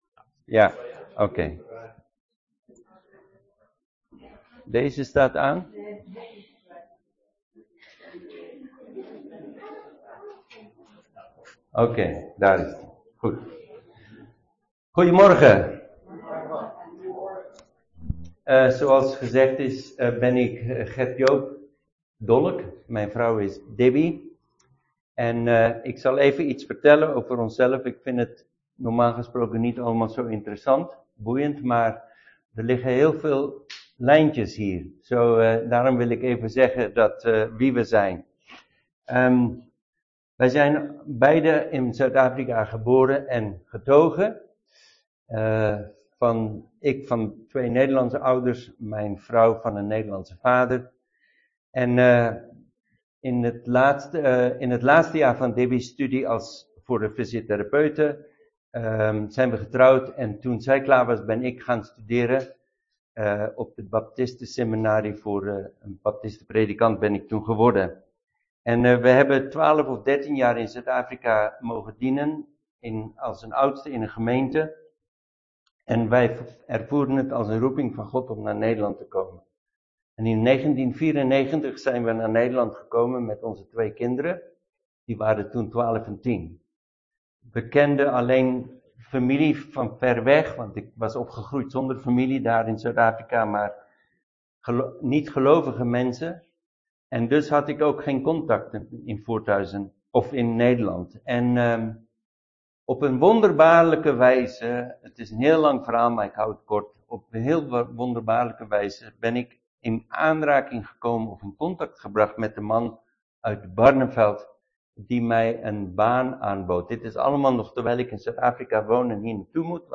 Een preek over 'Heeft de toekomst een kerk?'.